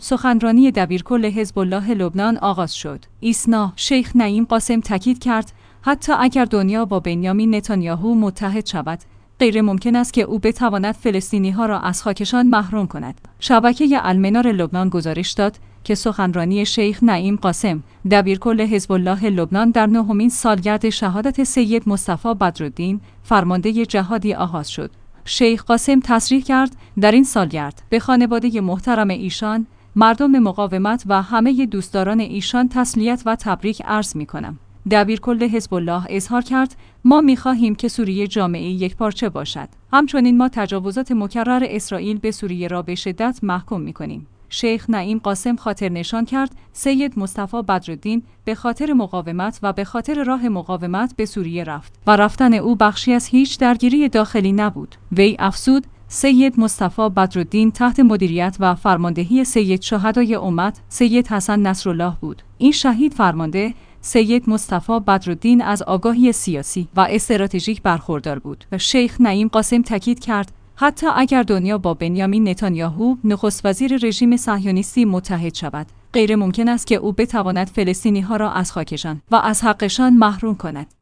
سخنرانی دبیرکل حزب الله لبنان آغاز شد